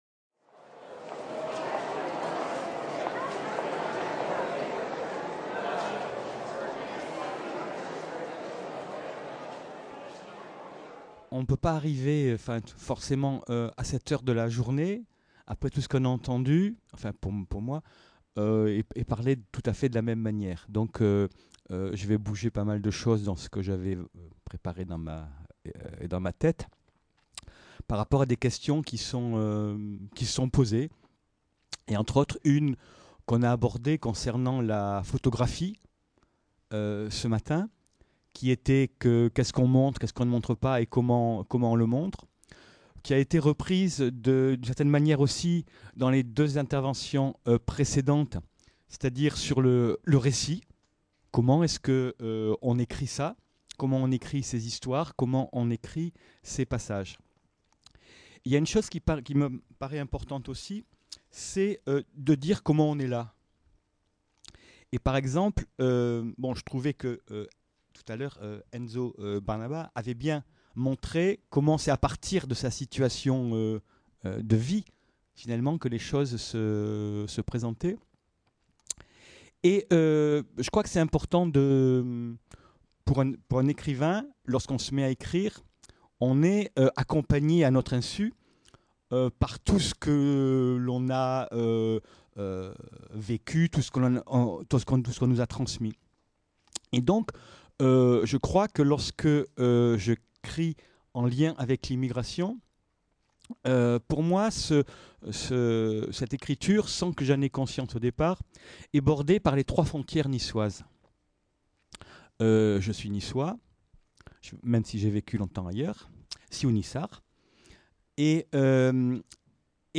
La frontière franco-italienne des Alpes-Maritimes, étudier les récits de traversées Journée d’étude organisée par l’Urmis et l’Observatoire des Migrations dans les Alpes-Maritimes à la MSHS de Nice, le jeudi 6 décembre 2018.